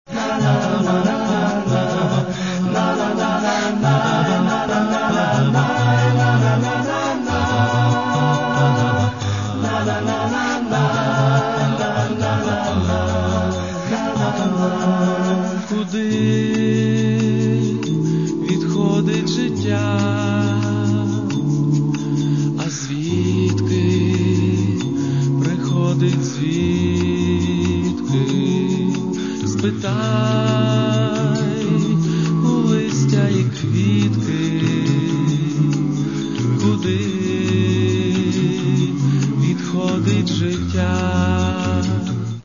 Каталог -> Другое -> Вокальные коллективы
Главное - что их пение завораживает снова и снова.